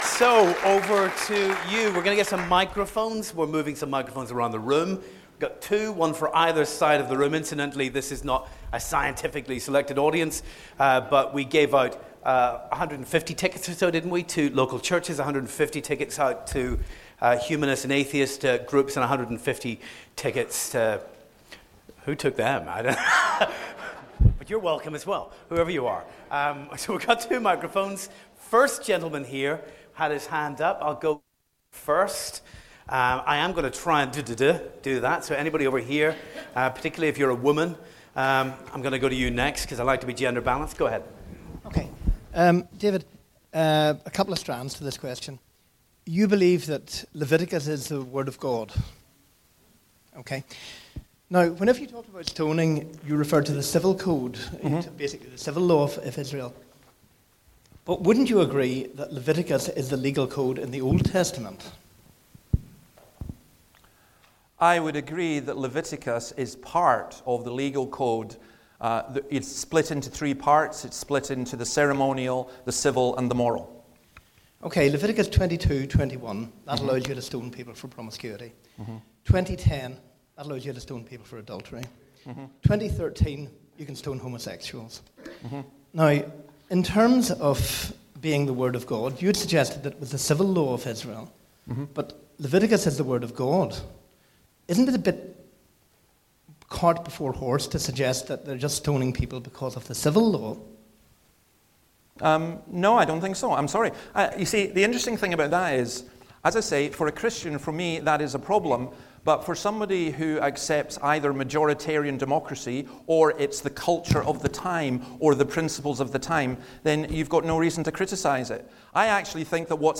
Audience questions